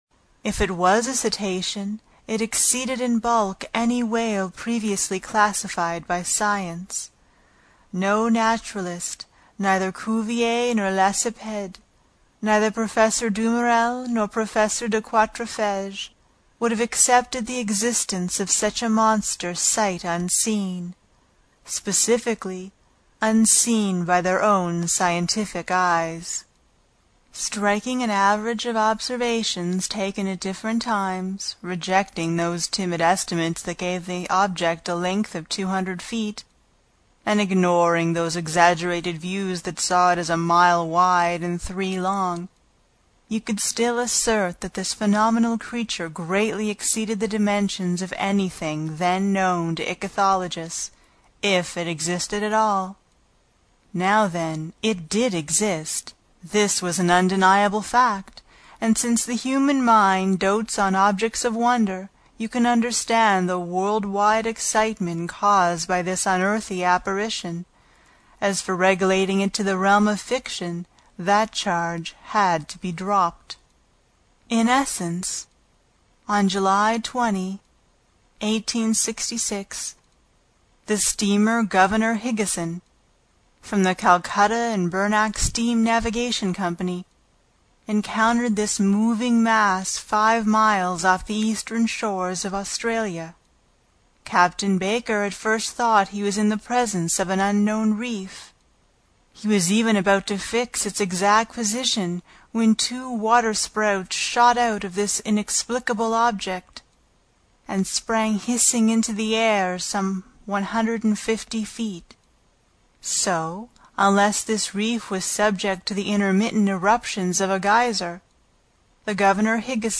英语听书《海底两万里》第2期 第1章 飞走的暗礁(2) 听力文件下载—在线英语听力室